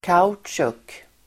Uttal: [k'a_otsjuk]